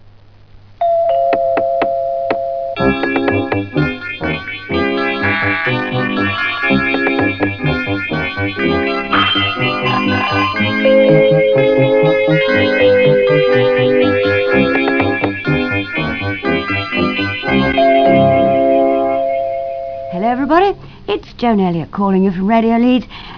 Electronic intro and voice intro
BBC Radio Leeds